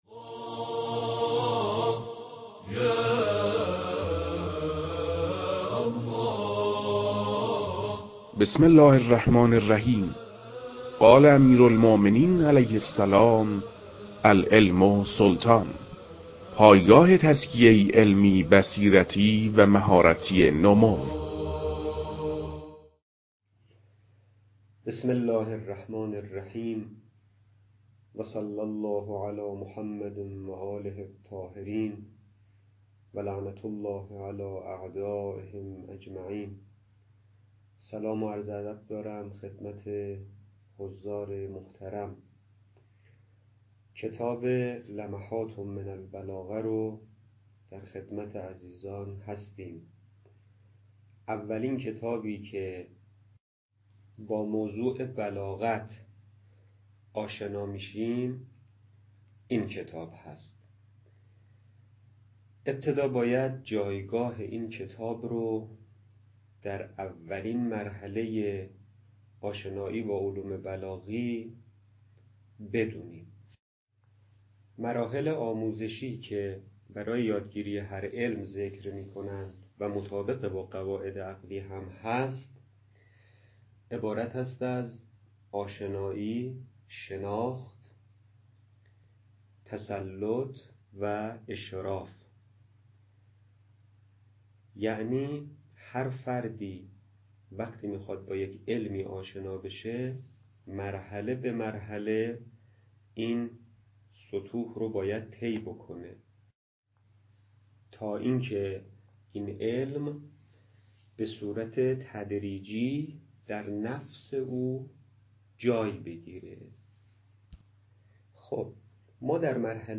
در این بخش، کتاب «لمحات من البلاغة» که اولین کتاب در مرحلۀ آشنایی با علم بلاغت است، به صورت ترتیب مباحث کتاب، تدریس می‌شود.
در تدریس این کتاب- با توجه به سطح آشنایی کتاب- سعی شده است، مطالب به صورت روان و در حد آشنایی ارائه شود.